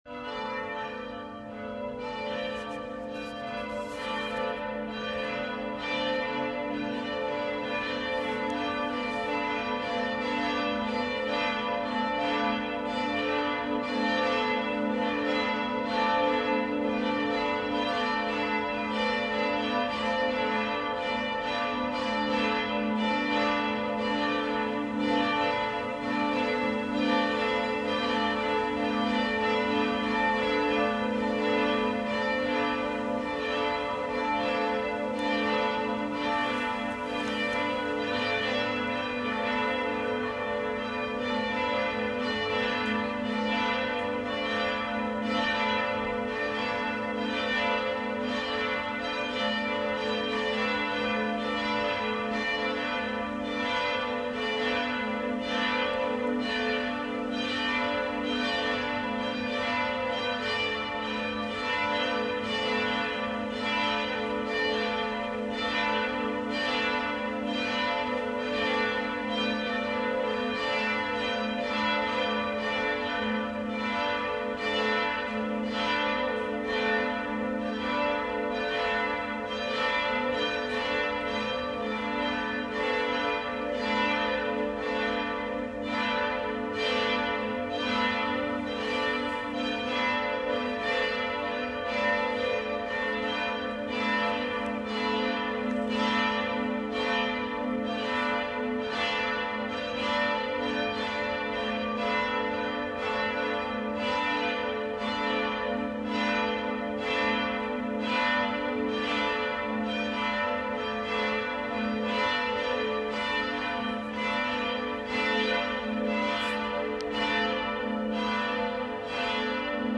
Die Glocken der Schutzengelkirche können mit unterschiedlichen Geläutemotiven erklingen.
Die sehr gute Harmonie, die hohe Vibrationsenergie der Glocken in Zusammenwirkung der schönen Akustik der Glockenstube ergeben eine Klangwirkung von außerordentlicher Klarheit, Fülle und Beseelung.
Geläutemotiv Gloria (Ruhm und Ehre):
Christkönig-Glocke, Marien-Glocke, Joseph-Glocke,